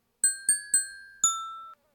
Diving_warbler.ogg